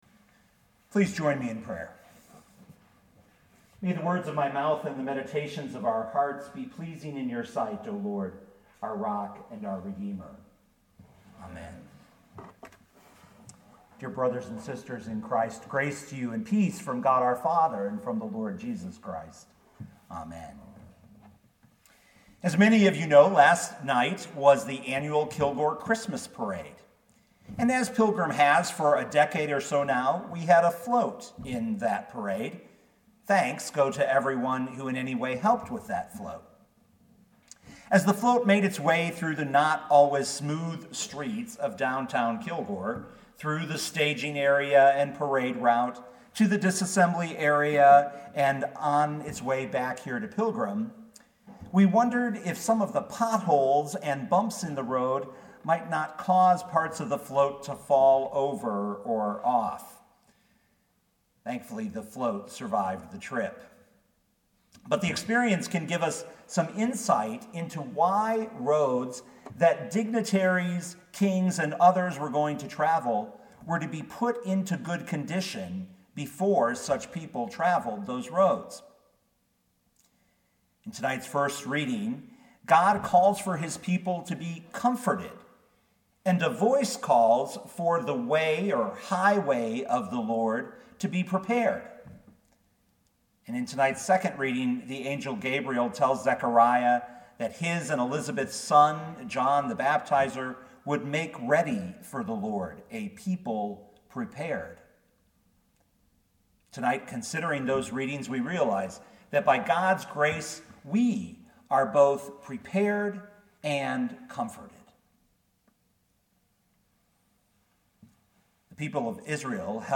Midweek Advent I
Lk 1:5-25 Listen to the sermon with the player below